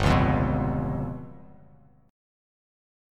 G7b5 Chord
Listen to G7b5 strummed